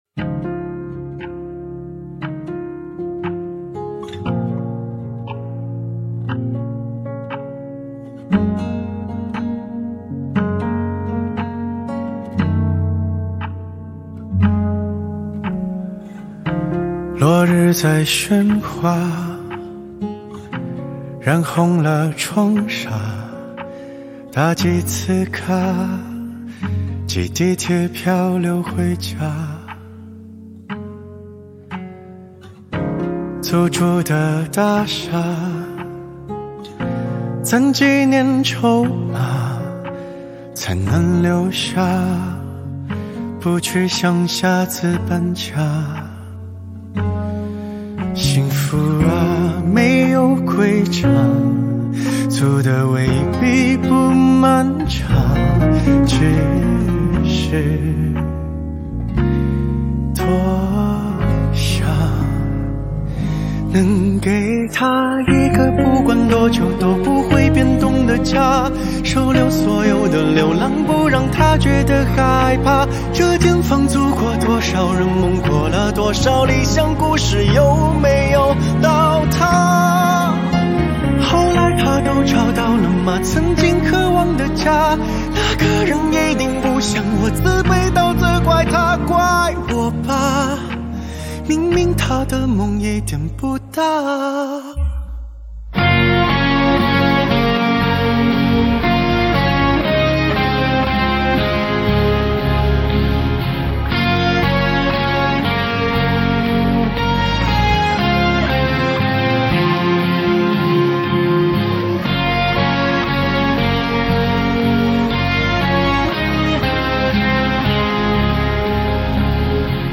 4/4 60以下
华语